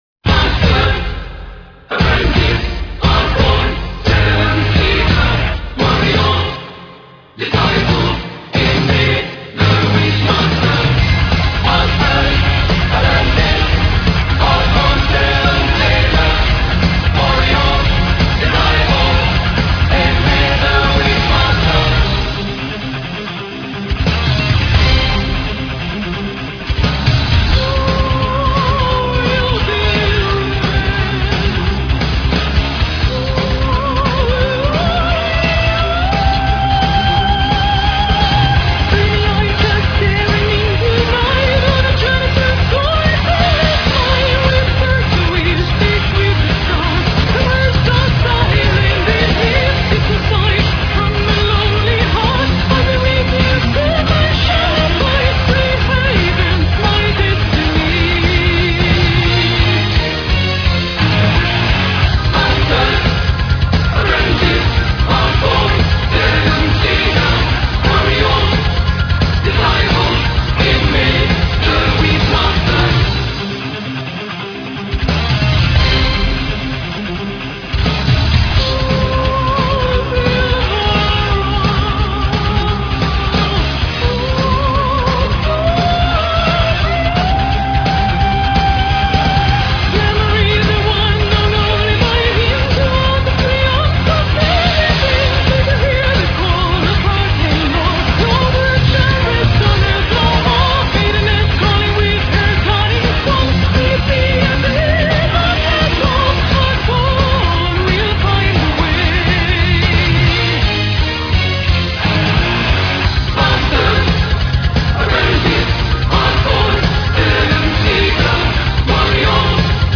("opera" metal)